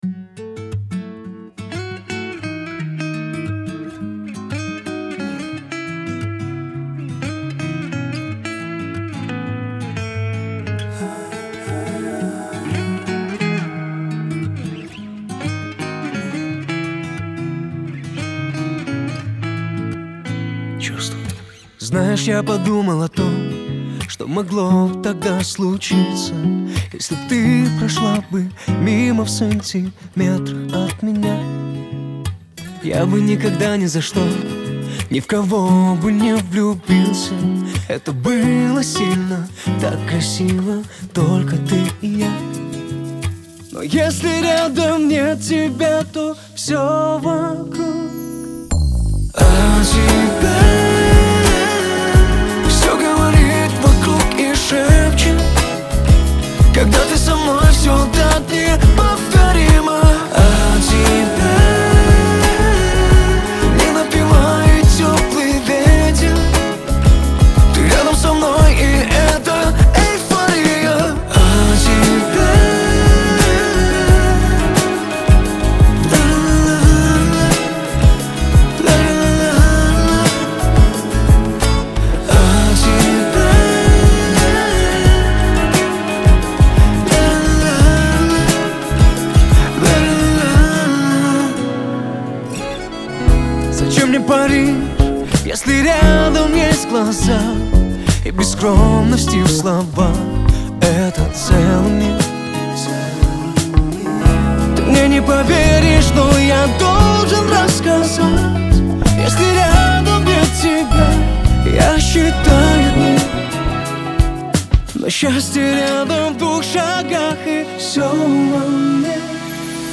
окутана аурой ностальгии и глубокой личной тоски